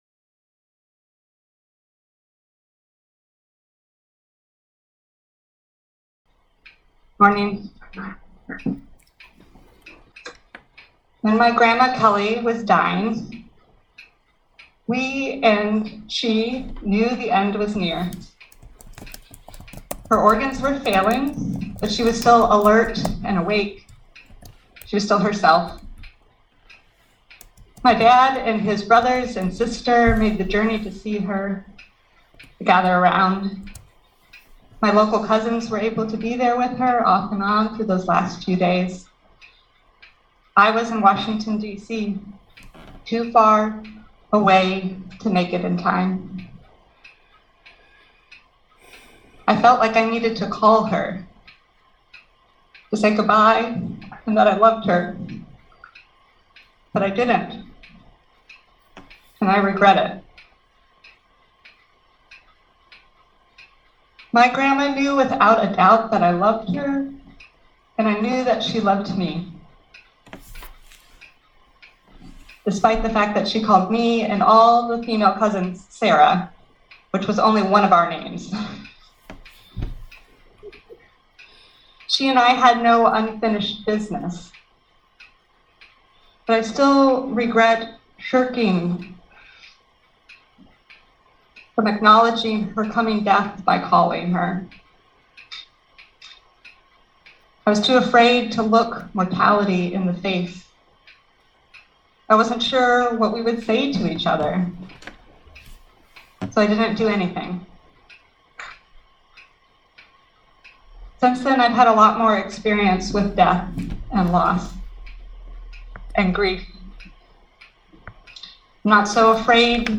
Listen to the most recent message from Sunday worship at Berkeley Friends Church, “What’s That Smell?”